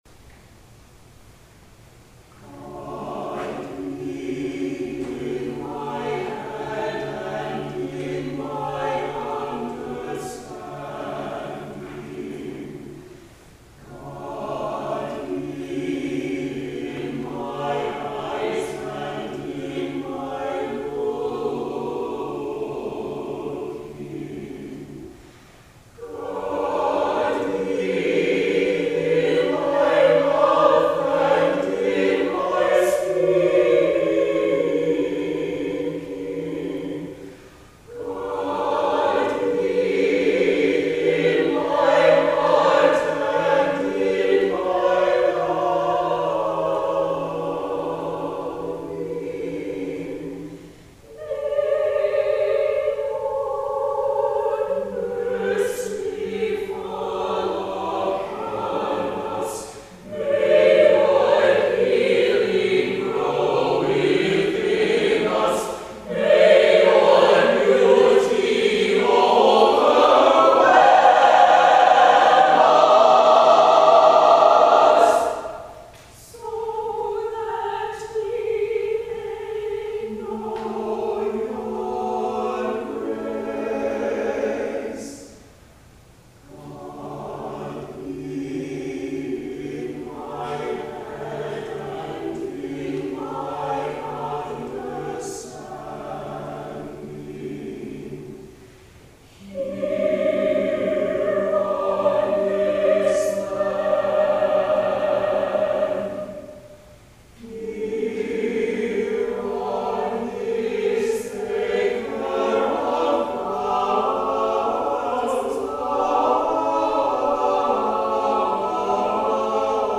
for SATB Choir